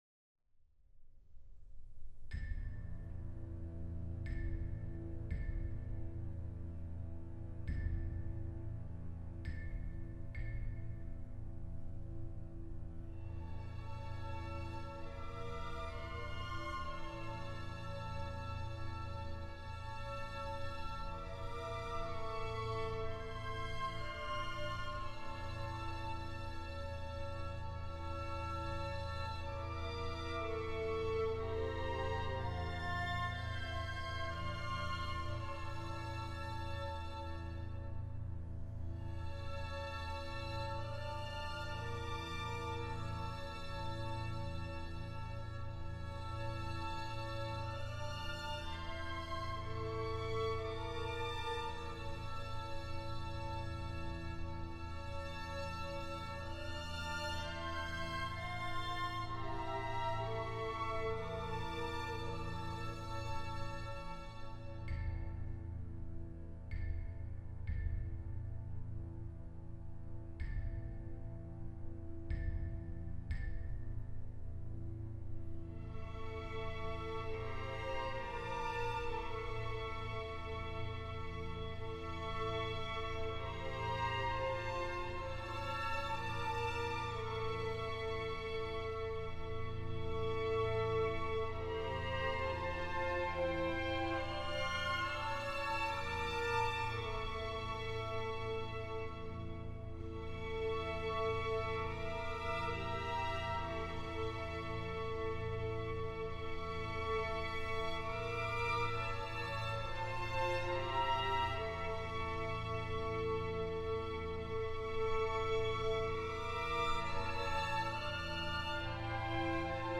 موسیقی کلاسیک